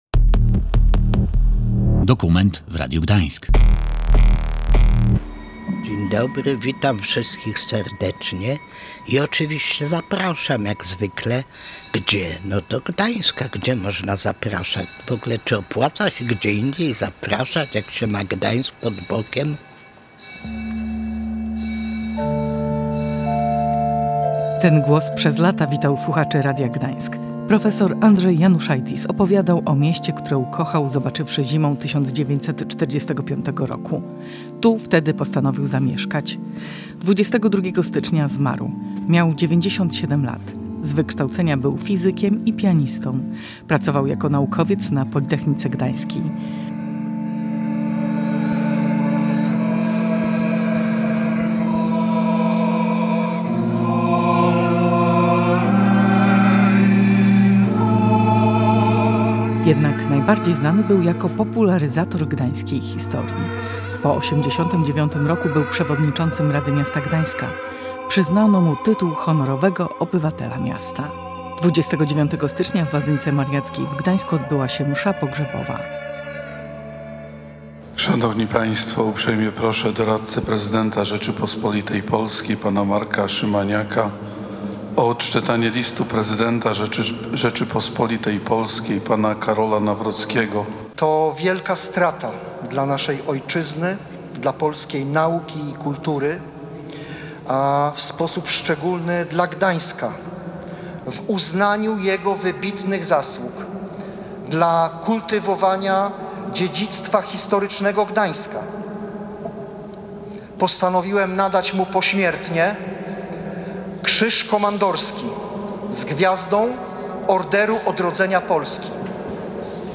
W audycji dokumentalnej zabierają głos